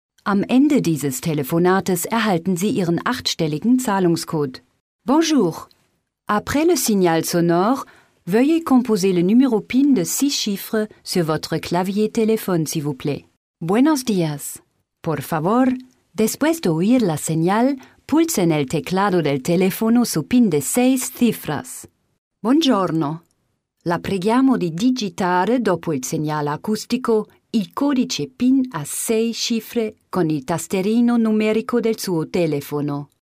Guten Tag, Hello, Bonjour, Buenos Dias ! meine Stimme ist warm, verbindlich und freundlich, perfekt für Ihr Voice Over, Industriefilm/Doku.
deutsch - englische (uk) Sprecherin.
Sprechprobe: eLearning (Muttersprache):